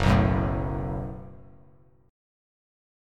F#mbb5 chord